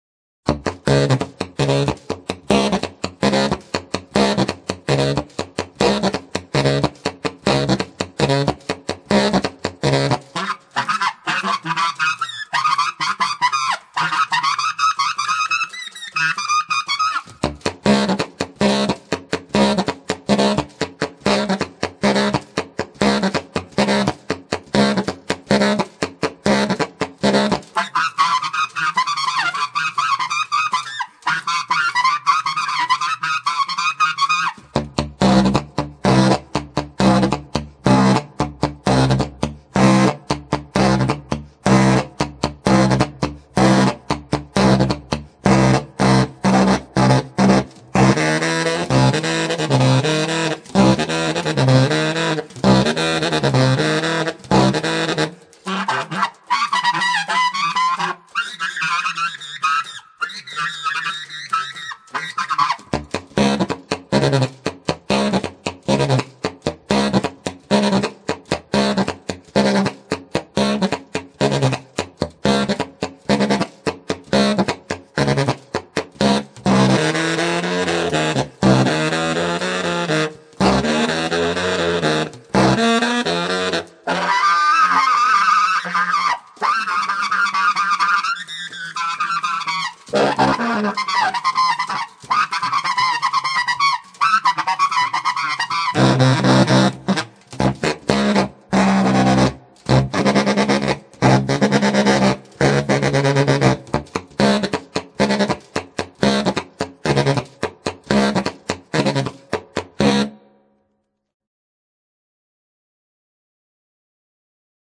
Recorded on April 20 & 21, 2000, at Colori Studio (Lessolo)
All inserts recorded live